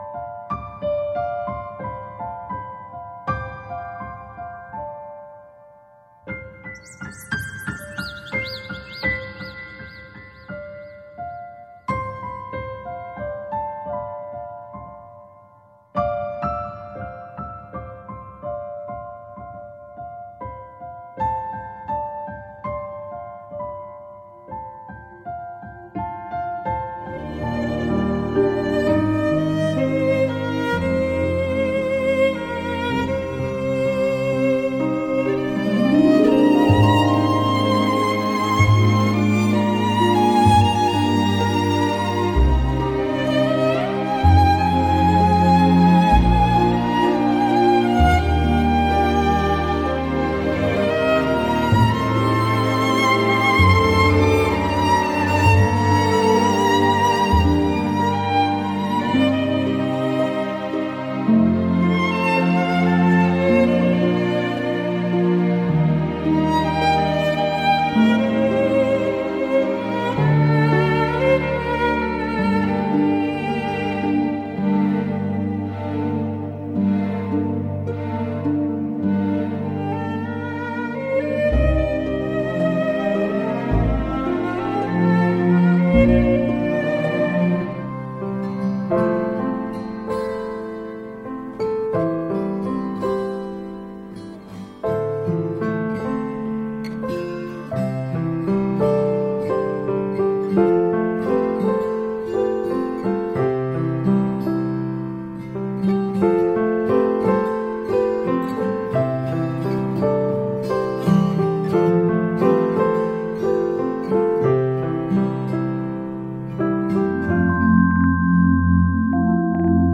Mellow After Massage Spa Music